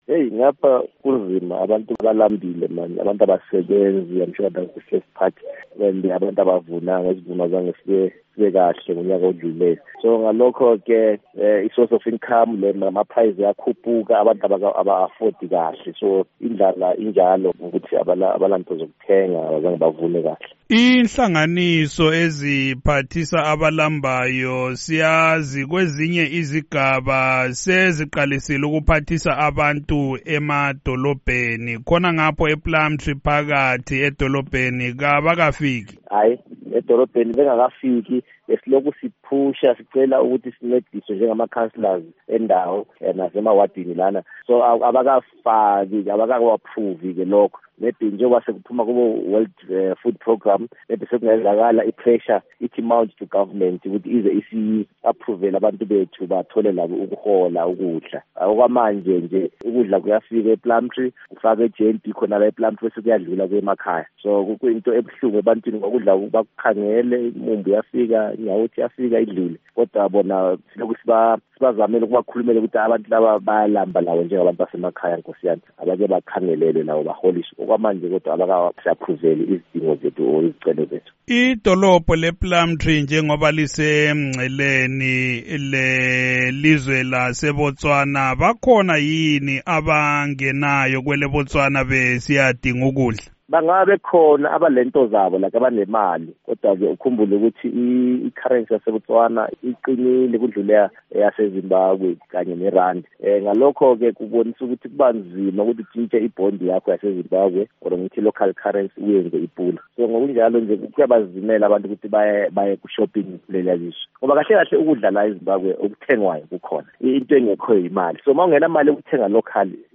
Ingxoxo loMnu Fanisani Dube